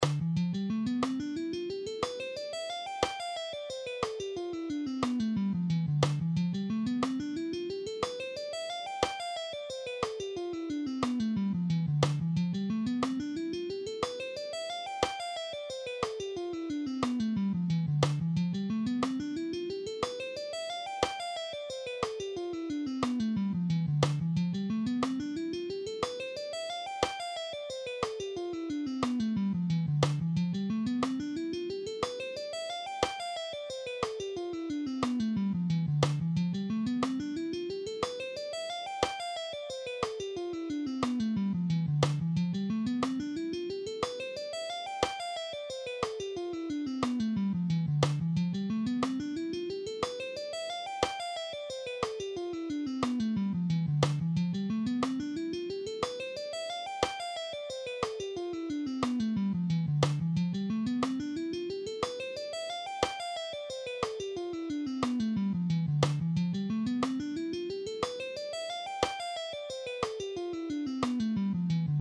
All these guitar exercises are in ‘C’
Dorian Economy Picking Guitar Lesson
2.-Dorian-Economoy-Picking-Guitar-Lesson.mp3